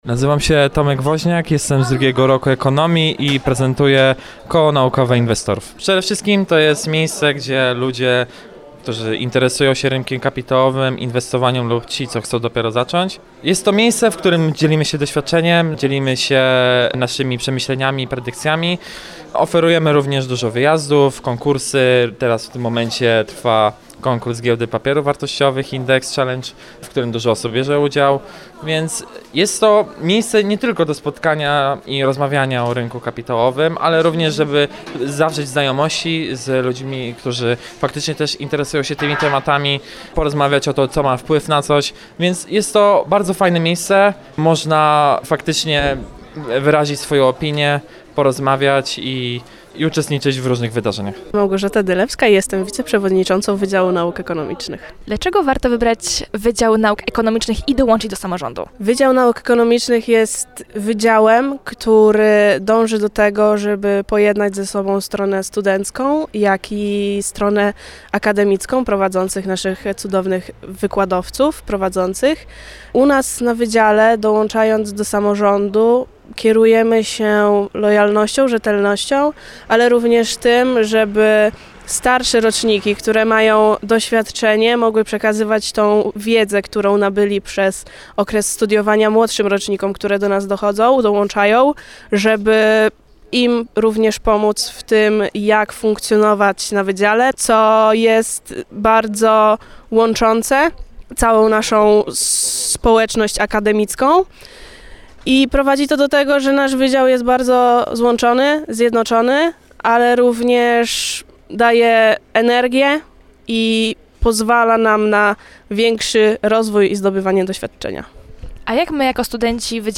W czwartek 16 kwietnia w siedzibie Wydziału Nauk Ekonomicznych UWM swoją ofertę prezentowały też koła naukowe i samorząd studencki. Rozmawialiśmy także z ich przedstawicielami.